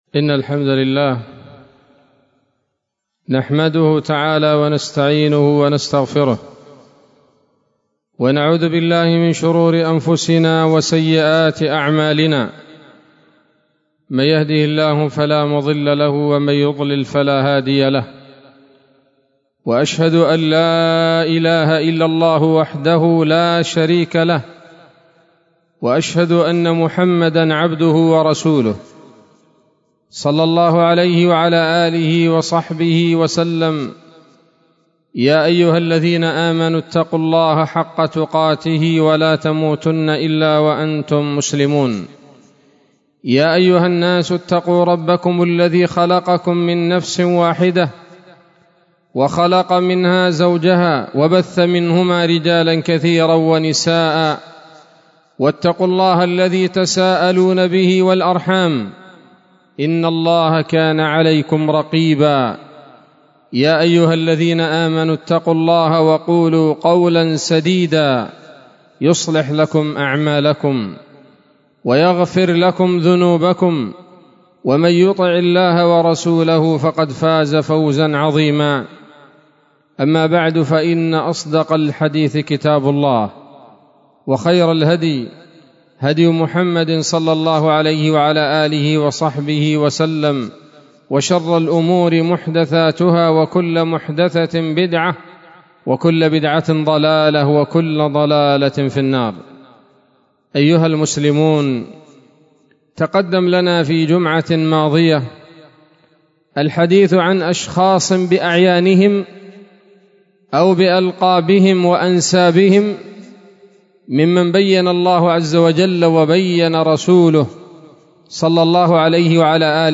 خطبة جمعة بعنوان: (( أسباب دخول النار [1] )) 04 ربيع أول 1444 هـ، دار الحديث السلفية بصلاح الدين